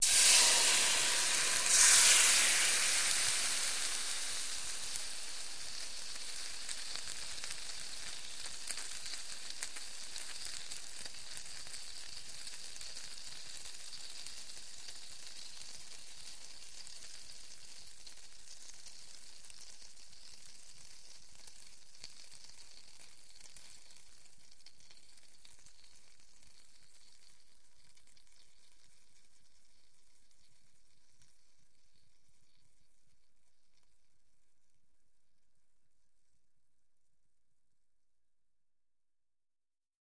Звуки сковородки
Шум жарящихся блинов на сковороде блиннице